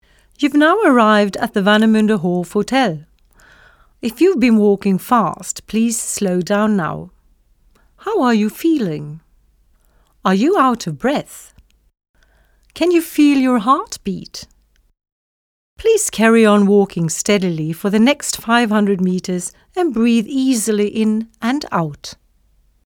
Audioguide
Enjoy your hike and the audio tour with easy exercises that you can do along the way!
Responsible for the sound recordings: Sound studio at Stralsund University of Applied Sciences.